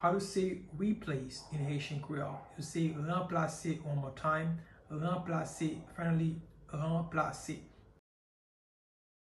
Listen to and watch “Ranplase” pronunciation in Haitian Creole by a native Haitian  in the video below:
Replace-in-Haitian-Creole-Ranplase-pronunciation-by-a-Haitian-tutor.mp3